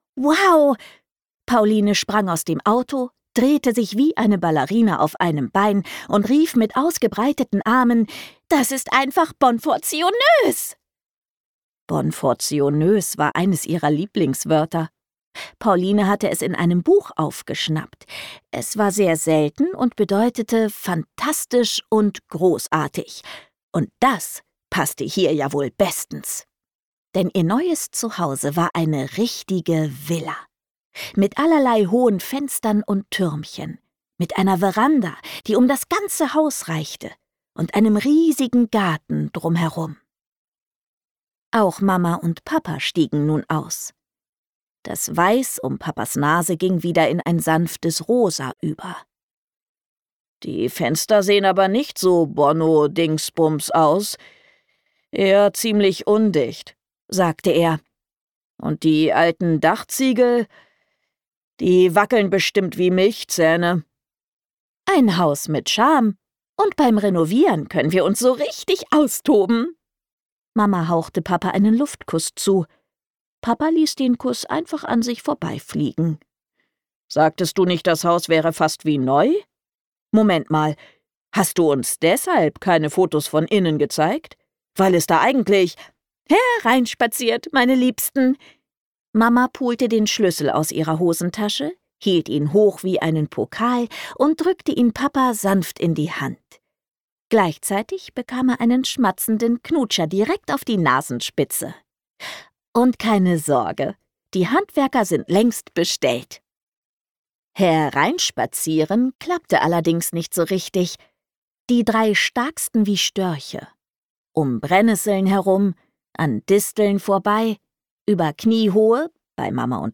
Hörbuch Print